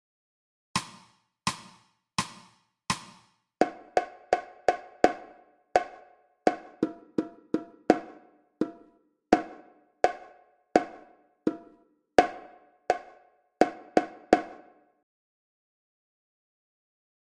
Dictat rítmic 3 (a dos altures).